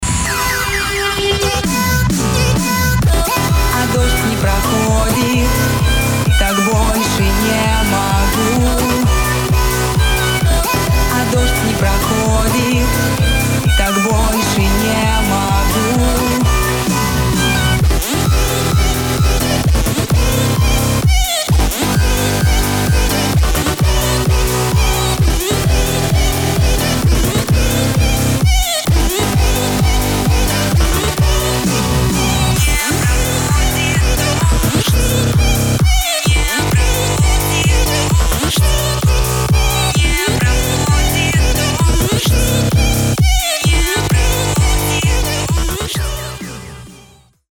громкие
dance
Версия песни для клубов и радио.